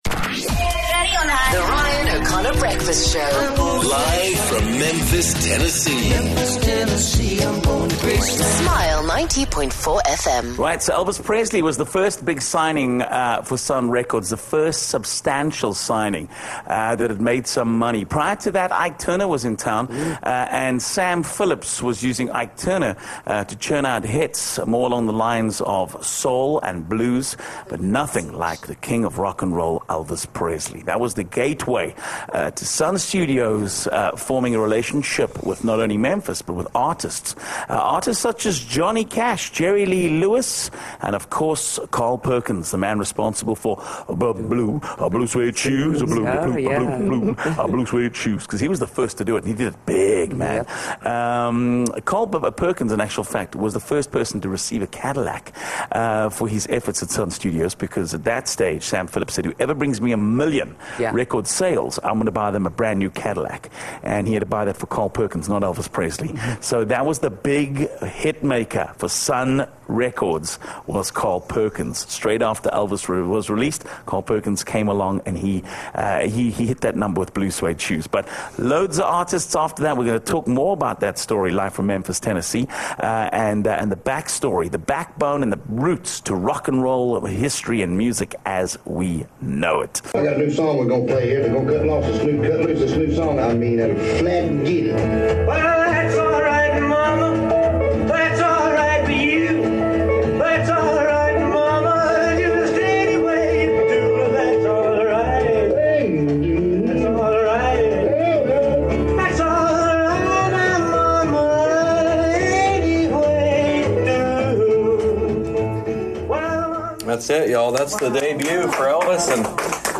18 Aug A tour of Sun Records in Memphis